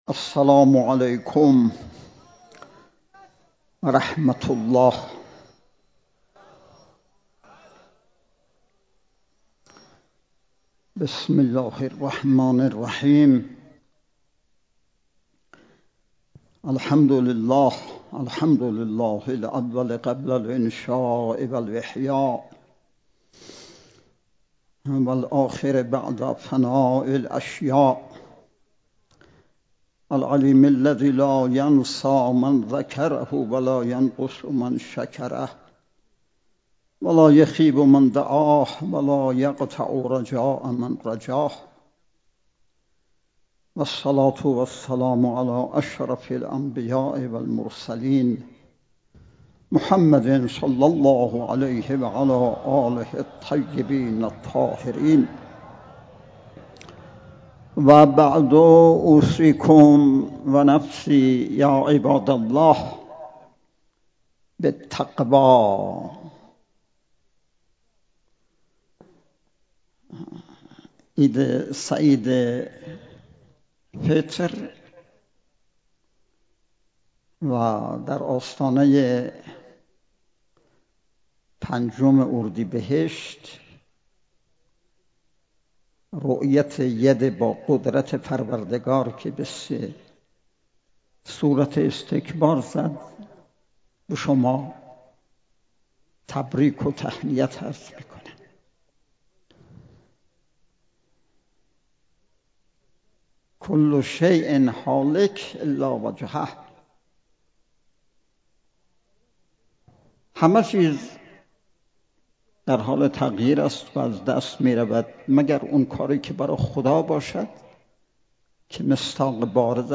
صوت کامل بیانات حجت الاسلام و المسلمن آقای حاج سید علیرضا عبادی در خطبه های نماز عید سعید فطر بیرجند
صوت/ خطبه های نماز عید سعید فطر بیرجند
خطبه-های-نماز-عید-سعید-فطر-1.mp3